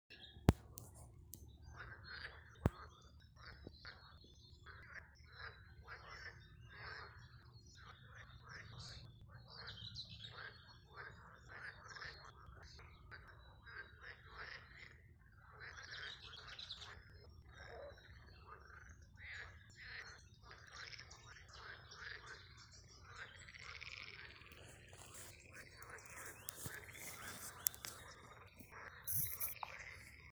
Brūnspārnu ķauķis, Curruca communis
StatussDzied ligzdošanai piemērotā biotopā (D)
Piezīmesfonā kurkst vardes